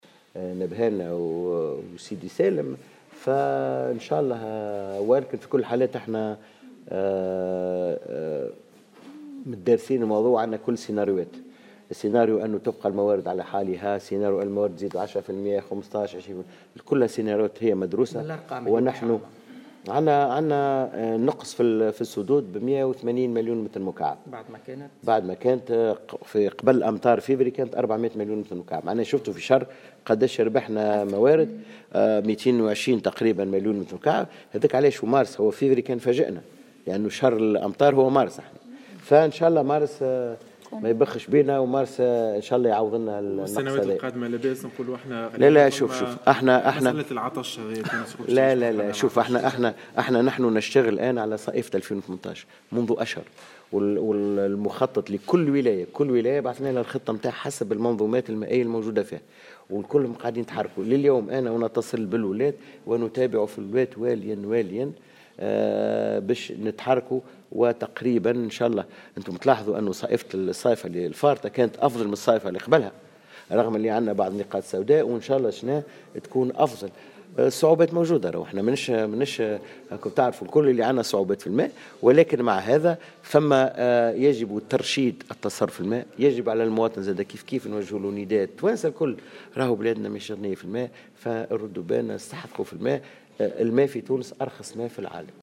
كما أبزر وزير الفلاحة، خلال نقطة إعلامية، اليوم الجمعة، أن الوزارة على استعداد لكل السيناريوهات لمواجهة أي نقص محتمل للمياه خلال ذروة الاستهلاك، حيث بادرت إلى إرسال مخططات خاصة بمتابعة مستويات المياه في كافة الجهات، مشددا على أهمية ترشيد استهلاك المياه بالنظر للفقر الذي تعانيه بلادنا على مستوى الموارد المائية.